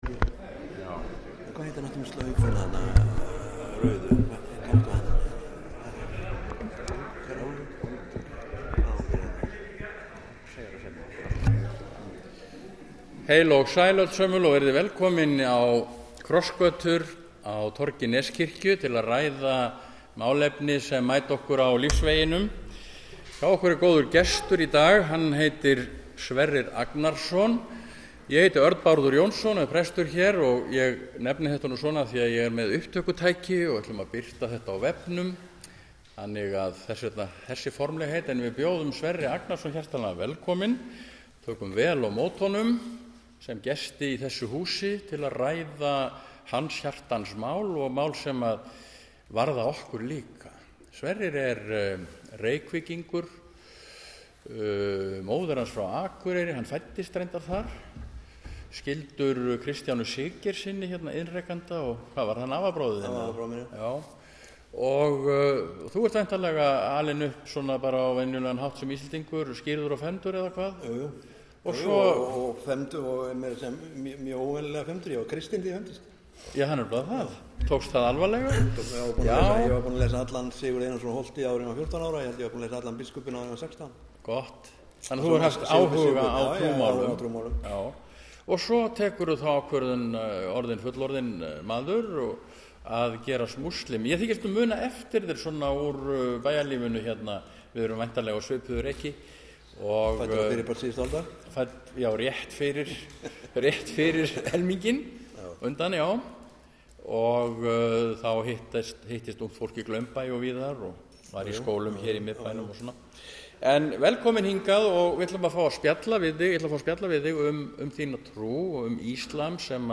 Samtal um islam